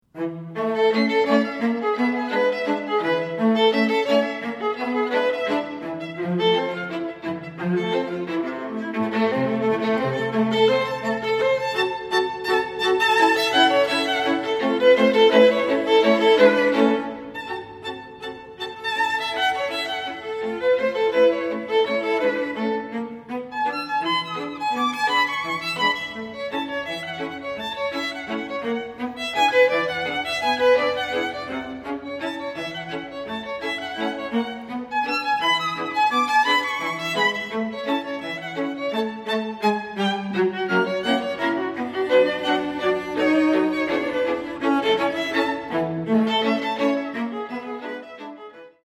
Violin
Viola
Violoncello